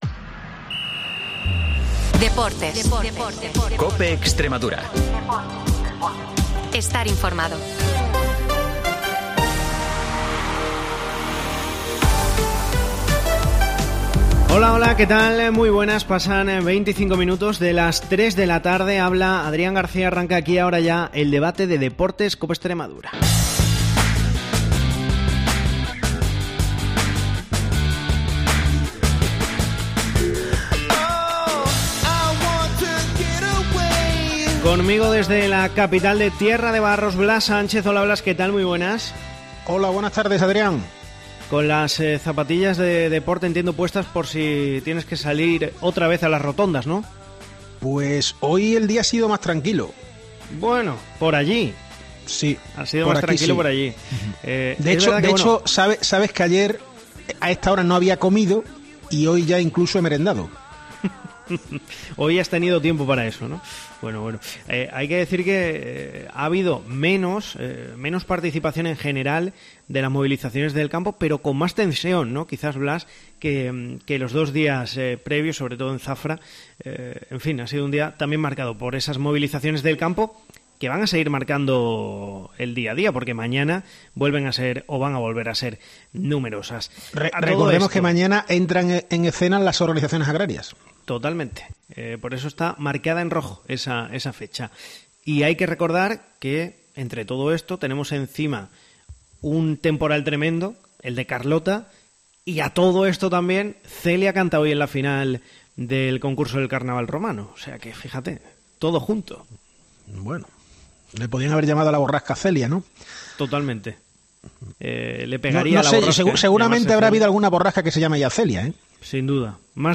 AUDIO: El debate de deportes de COPE Extremadura